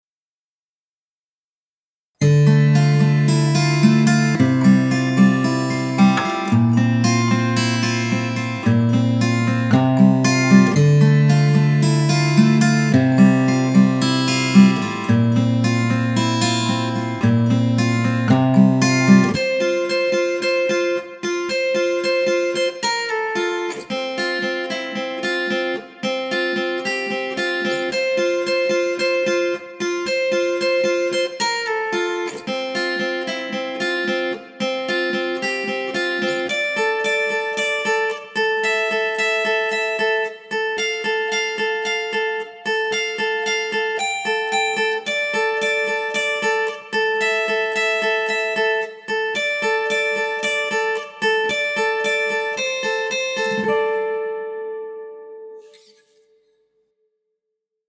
Guitarist & Songwriter from Argentina
Beautiful Arpeggio